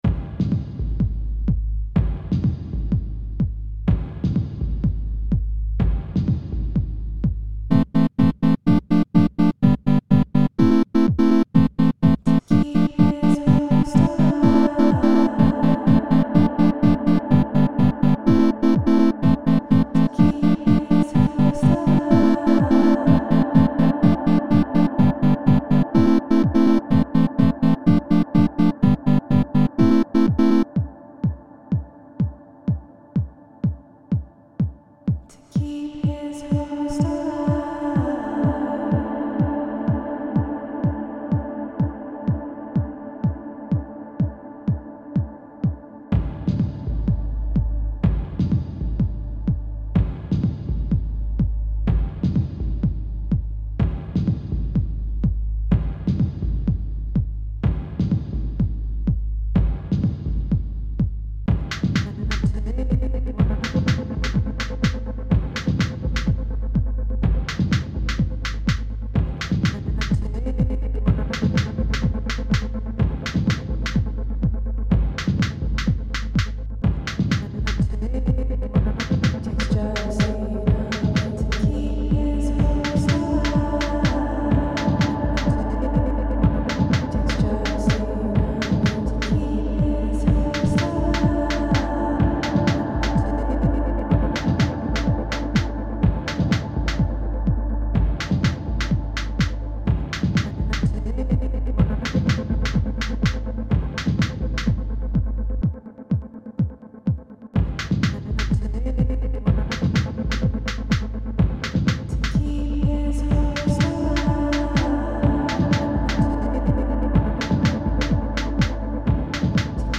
I found this song on my old hard drive and decided to remix it, the original is a song I made probably a couple years or so ago, but I decided to import the tracks into ableton and use some of the live mixing techniques that ableton kind of forces you to do, so I made them into loops and re-mixed them. I also added in some midi chord progression with a lead using the tweakbench intruments again.
The vocals on this say "the tapeworm, takes just enough, to keep his host alive" When I heard it again after so long, I realized it's a really weird and kinda cheesy song, but I thought that maybe with some heavy reverb on the vocals it would muddy up the vocals enough to not make them seem so literal.
i like it :) especially the chimey part around 4:00